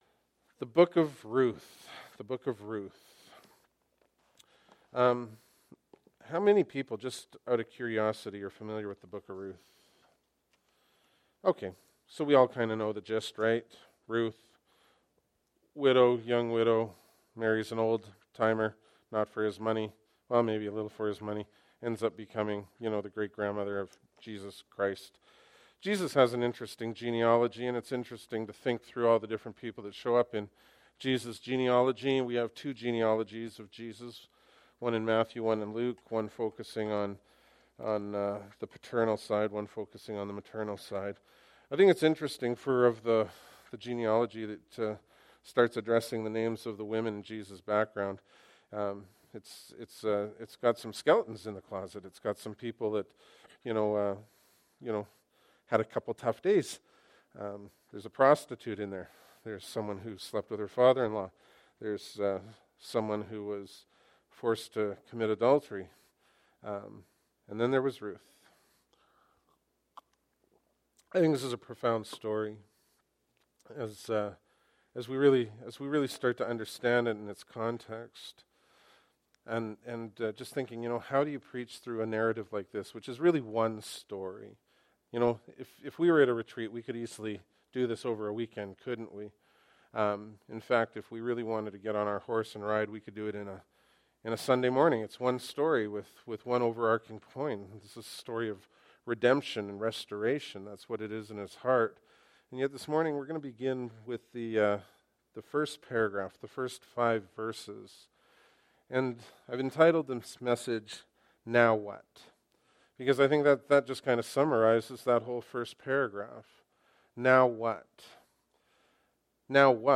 Bible Text: Romans 5:1-5 | Preacher